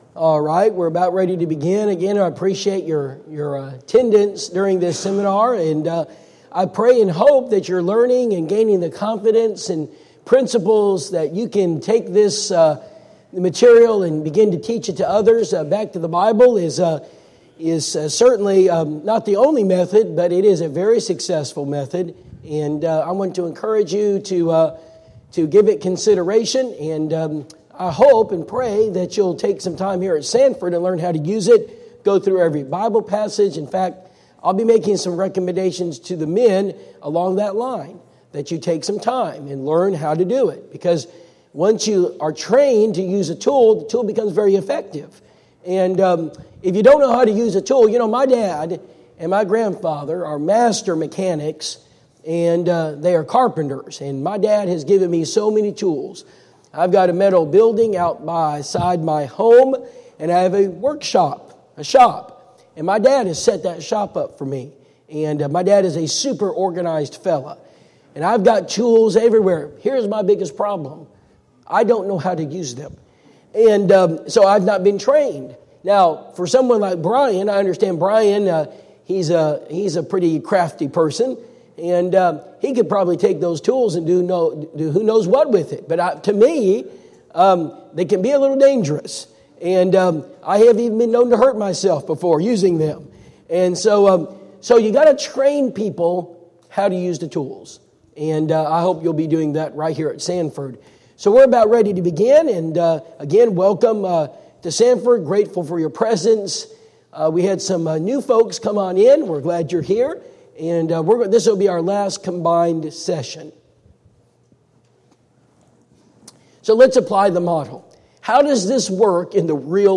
Personal Evangelism Seminar Service Type: Personal Evangelism Seminar Preacher